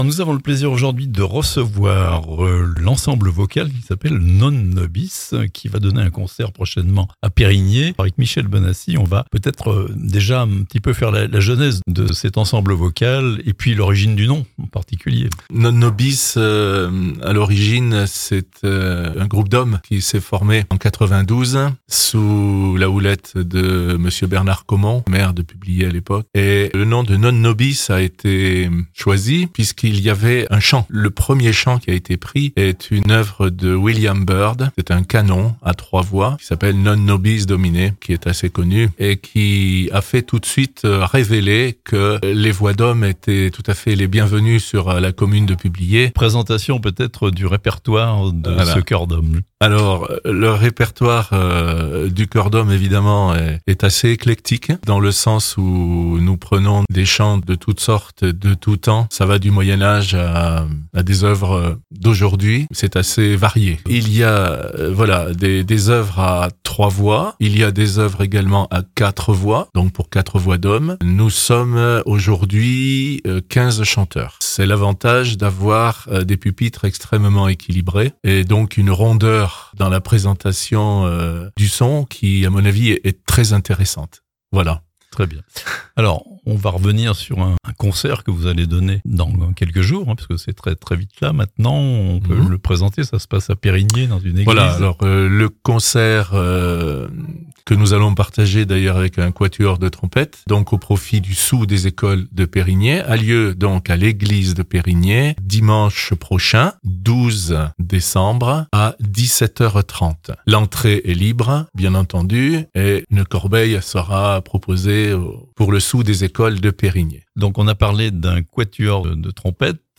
Un concert pour voix d'hommes et trompettes à Perrignier (interviews)